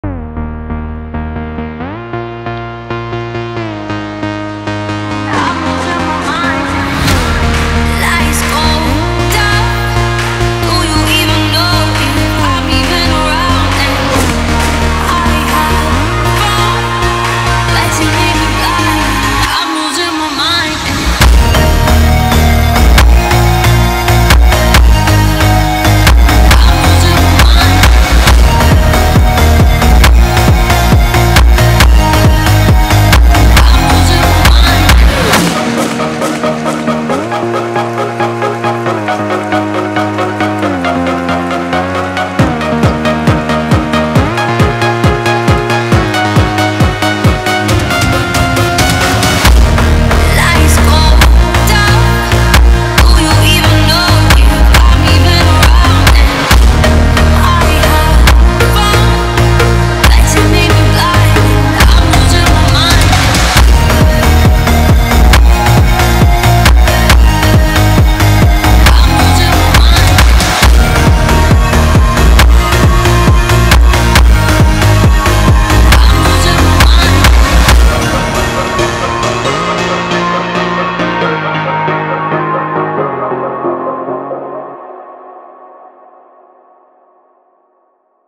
сочетая элементы R&B и поп-музыки.